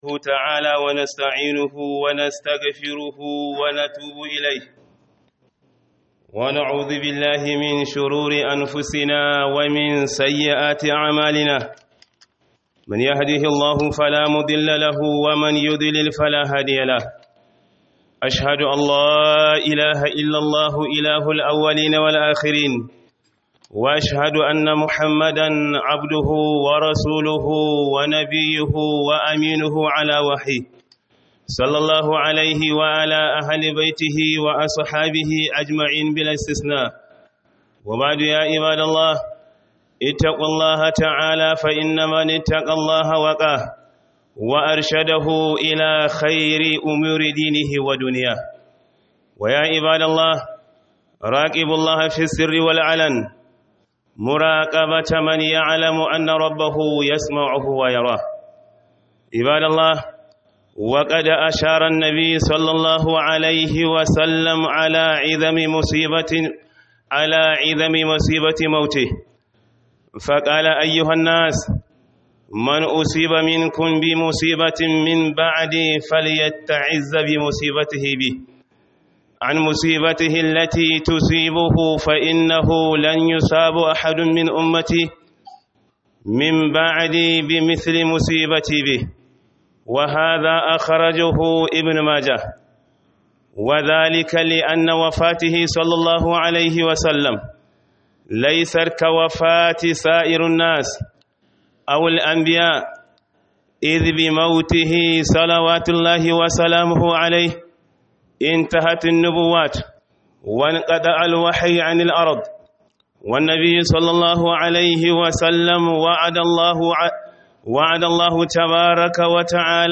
Khudubar Low-cost Ningi - Musibu bayan Wafatin Manzon Allah